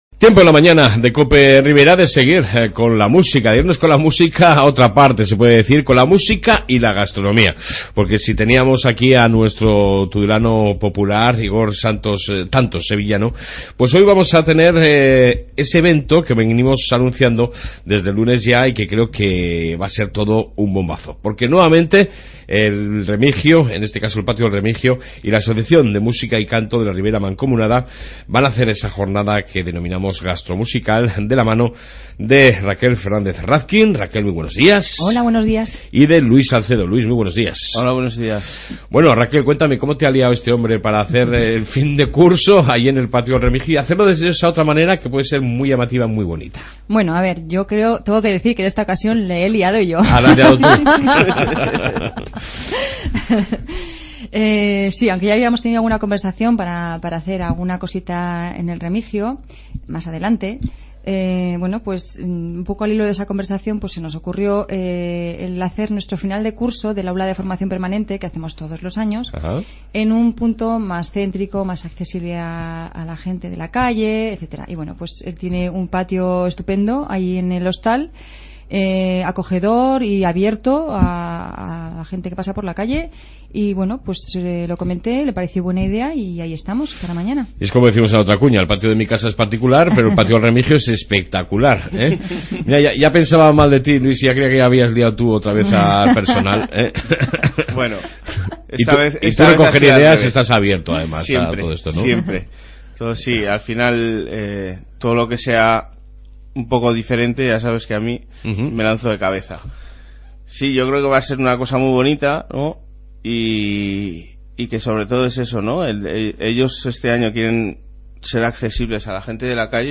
AUDIO: Entrevista con La Asociación Música y Canto de la Ribera con motivo de su fin de curso en "El Patio del Remigio"....Gastronomía y...